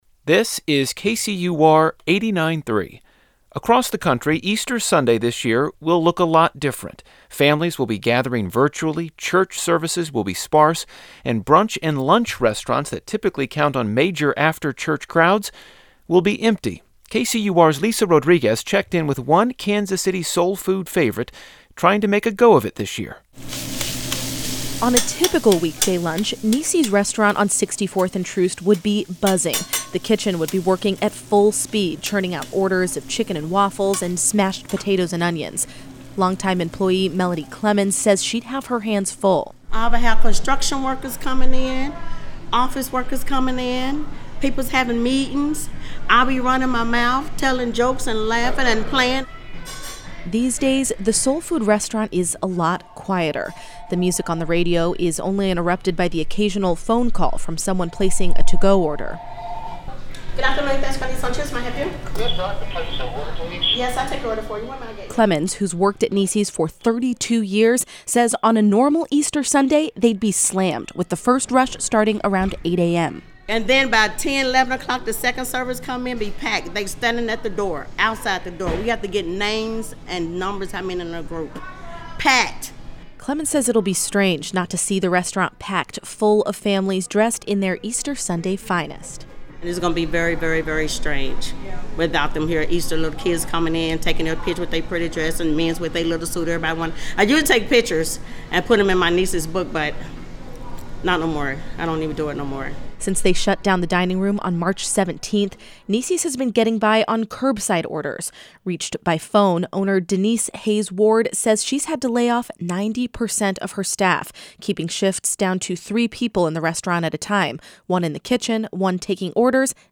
The music on the radio is only interrupted by the occasional phone call from someone placing a to-go order.